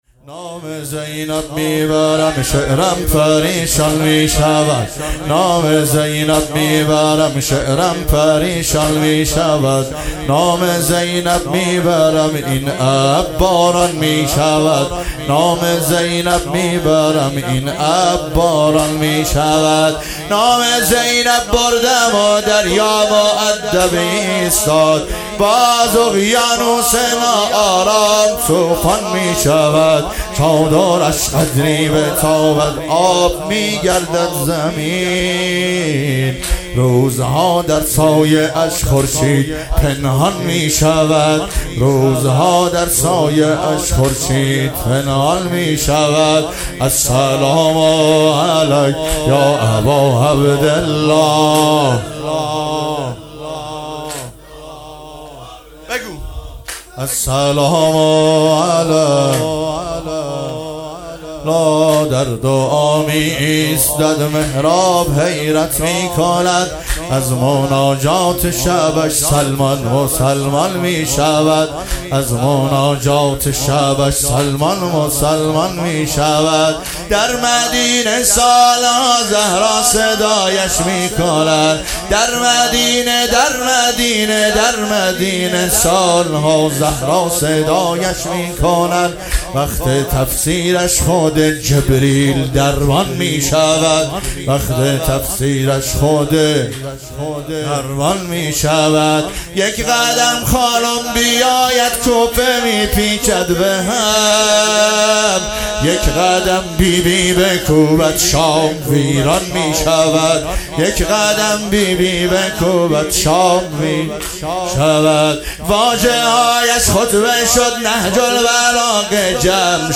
شب سوم دهه سوم محرم ۱۴۰۰